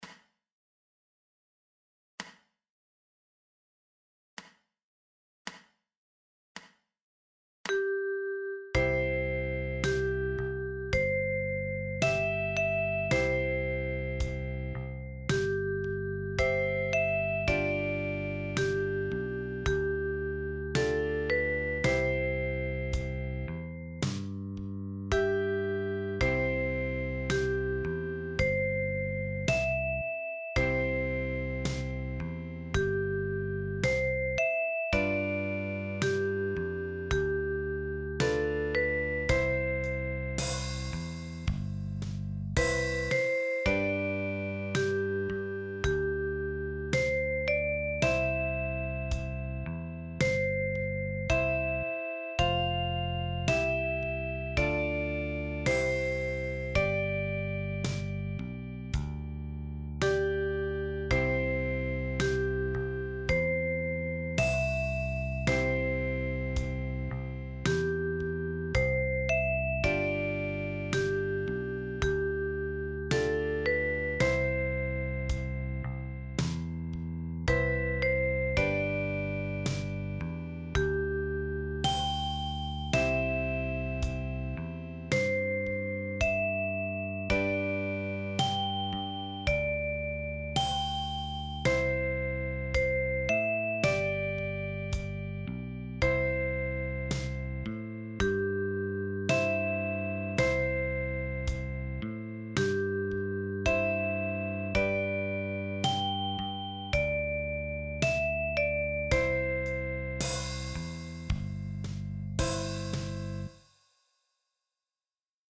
Für Altblockflöte in F.